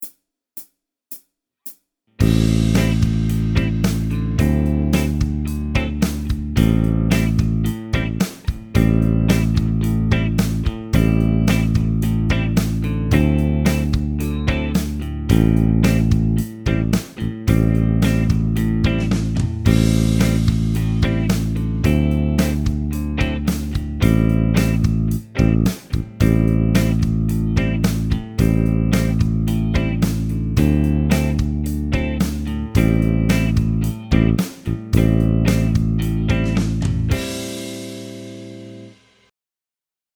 bends-and-slides-backing